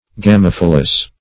Search Result for " gamophyllous" : The Collaborative International Dictionary of English v.0.48: Gamophyllous \Ga*moph"yl*lous\, a. [Gr.